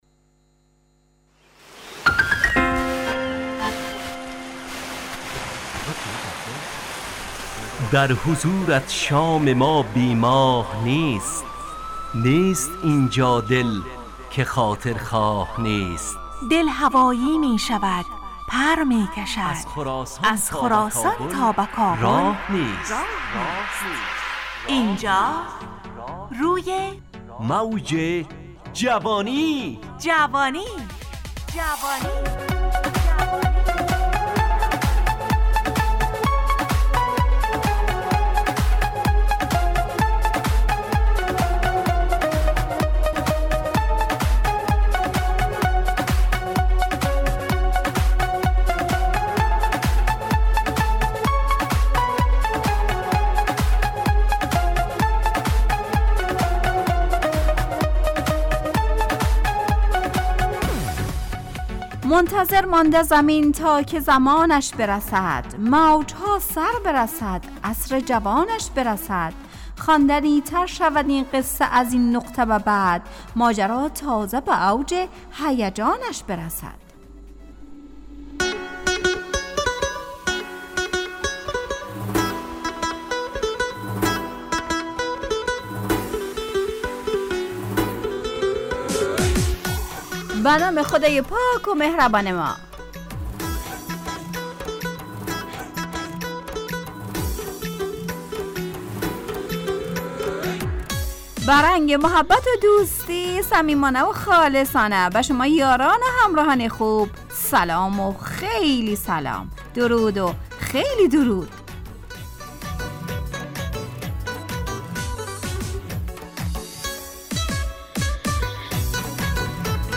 همراه با ترانه و موسیقی .